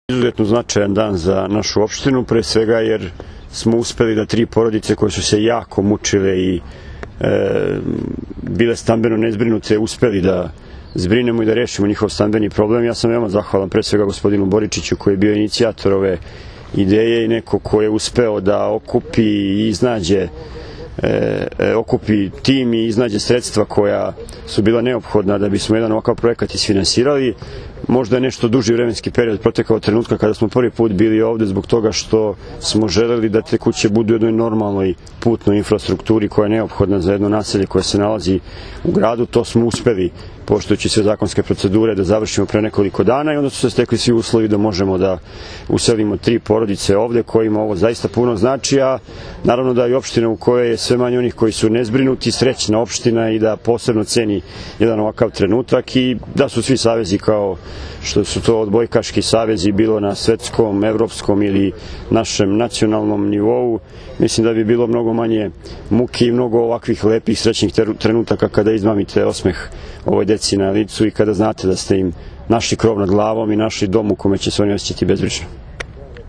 IZJAVA DARKA GLIŠIĆA